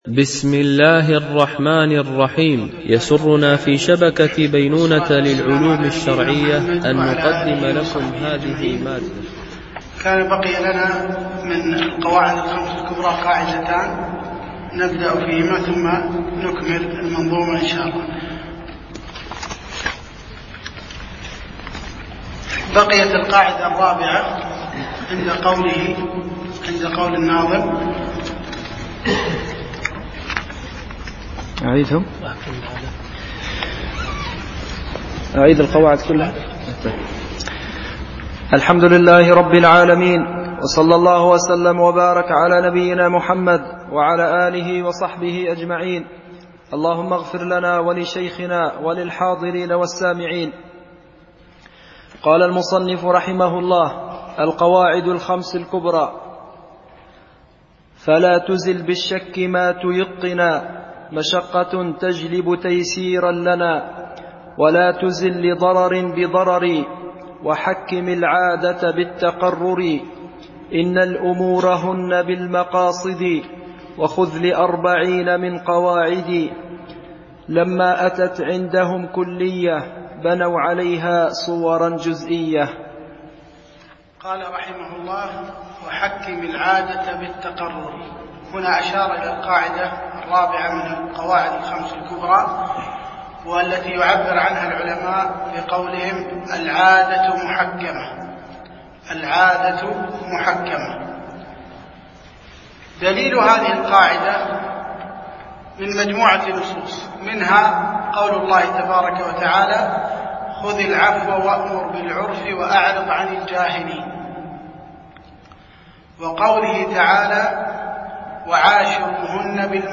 شرح منظومة القواعد الفقهية ـ الدرس الثاني